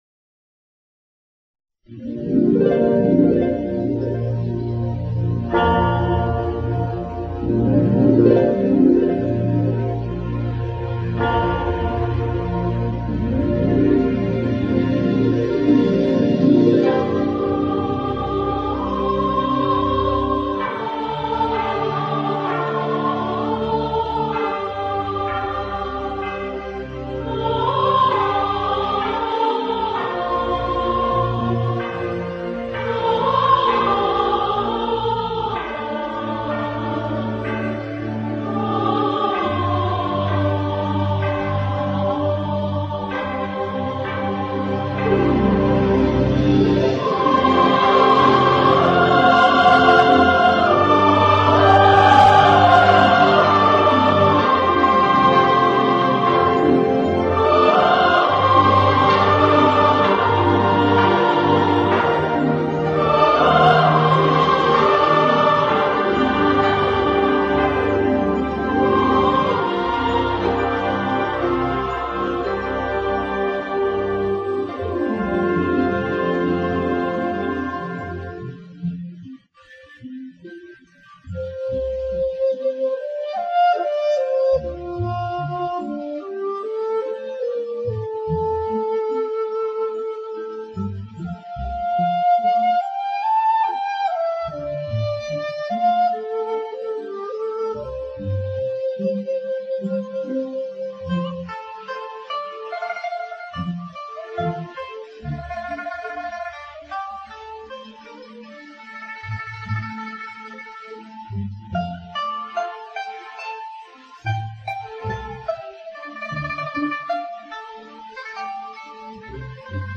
而结尾部分以交响乐为主，配以钟鼓，合唱加入了男声，使声音更加浑厚，并归复于平静。